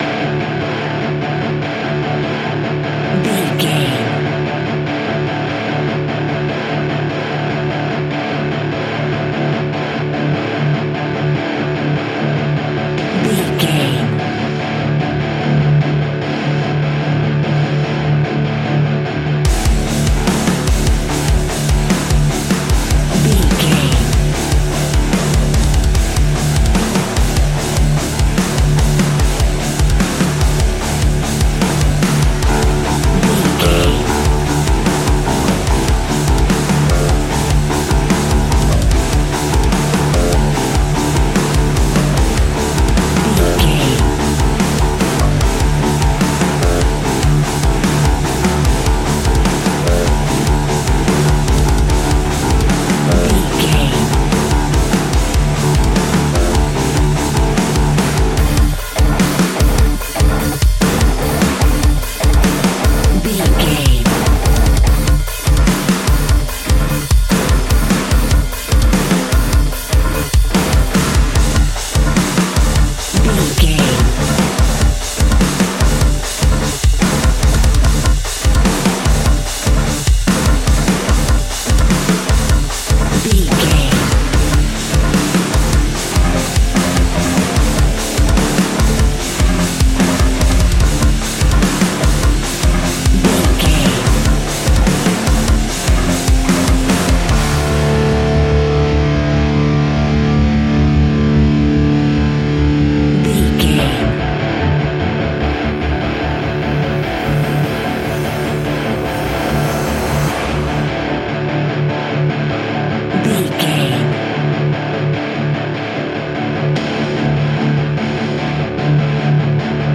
Ionian/Major
D♭
hard rock
guitars